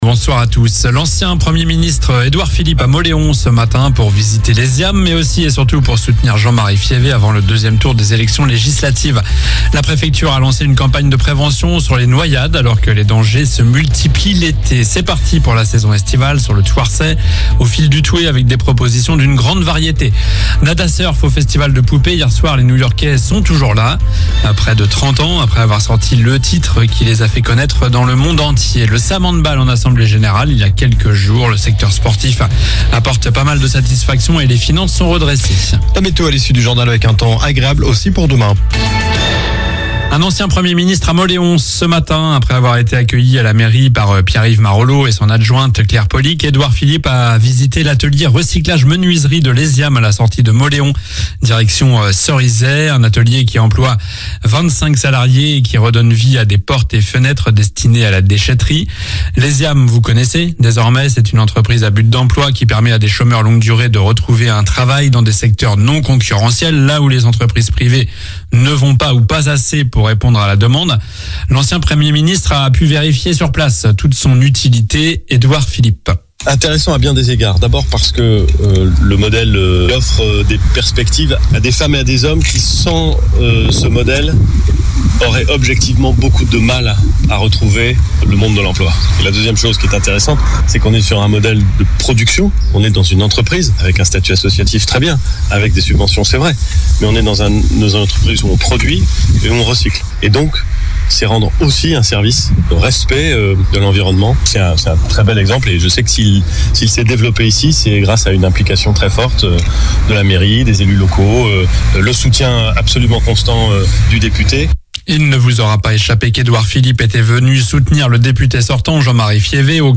Journal du jeudi 04 juillet (soir)